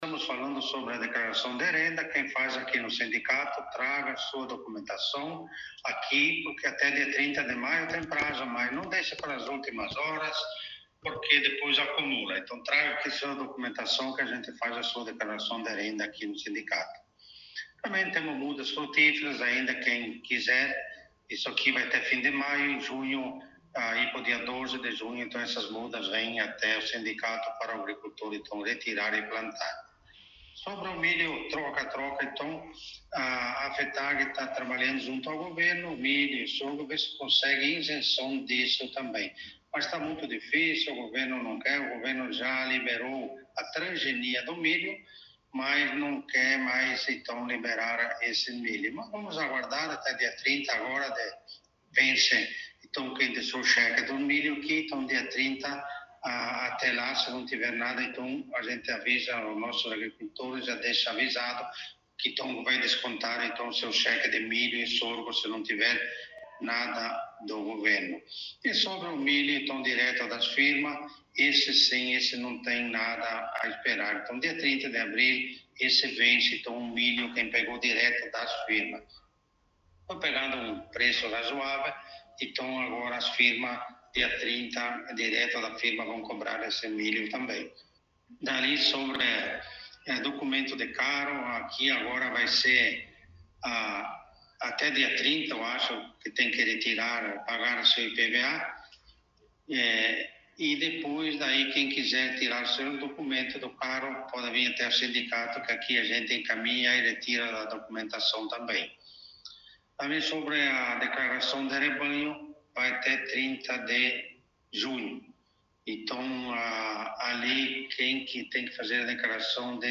Presidente do Sindicato dos Trabalhadores Rurais concedeu entrevista